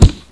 sounds: new punch sounds. ... I'm still stuck with grossly subpar recording gear right now, but this is urgent (or rather *long* overdue) as per discussion in #1486 . DSPUNCH is just me punching myself in the arm run through a *bunch* of stuff in Saucedacity until it started to sound like a punch ingame instead of a weird tinny click.
dspunch.wav